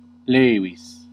Ääntäminen
IPA : /ˈfɪk.əl/